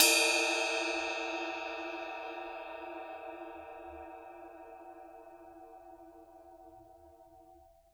susCymb1-hitstick_mp_rr1.wav